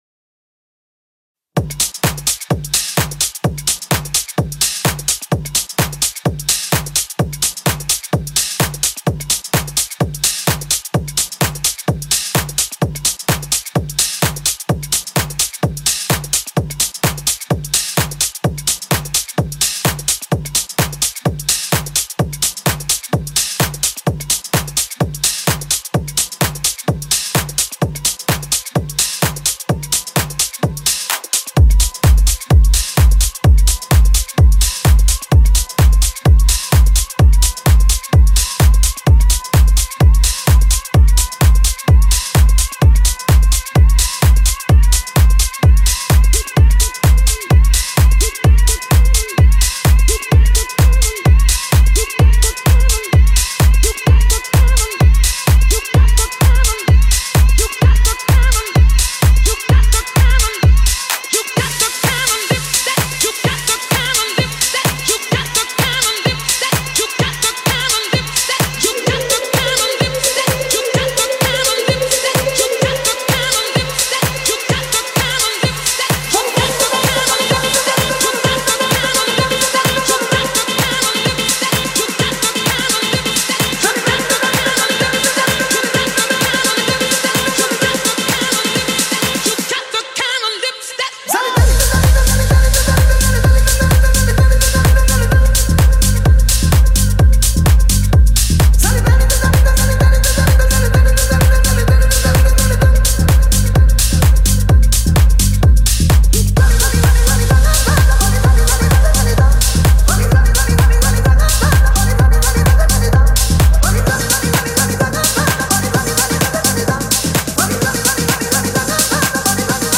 • Category: Bollywood DJ Mashup
• Style: Emotional / Mashup Mix
• Audio Quality: High Quality Sound